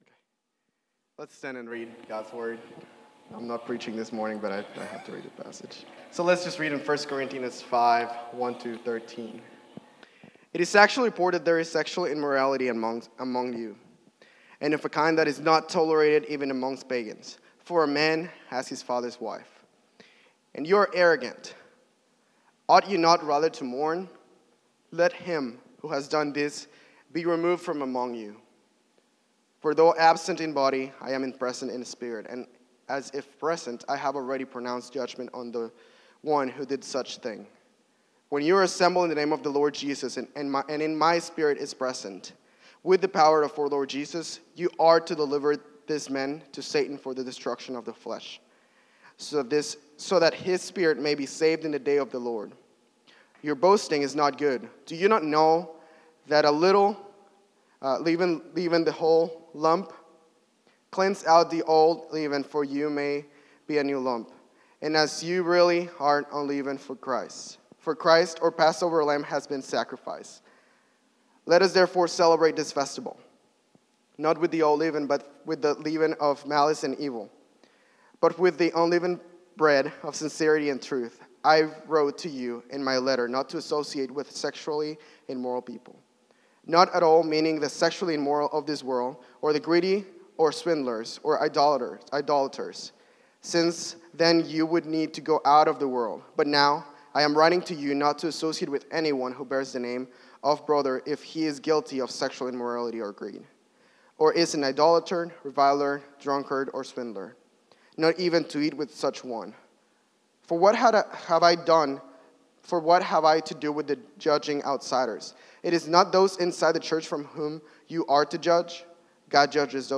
Sermons | Hazelwood Baptist Church